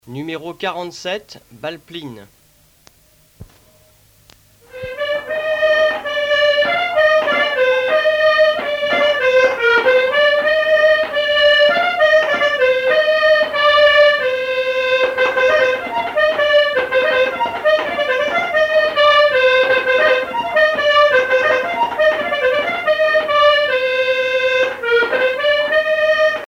danse : plinn
Pièce musicale éditée